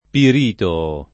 [ pir & to-o ]